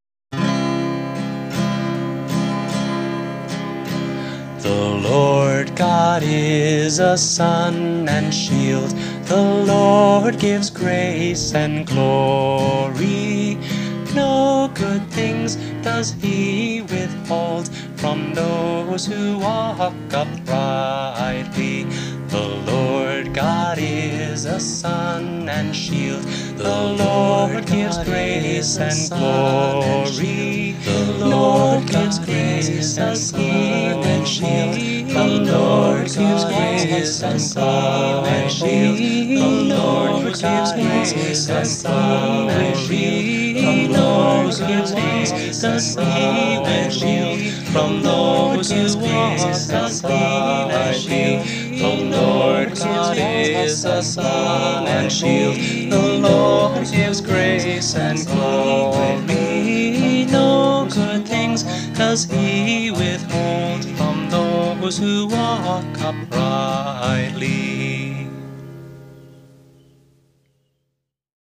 (four part round, Psalm 84:11)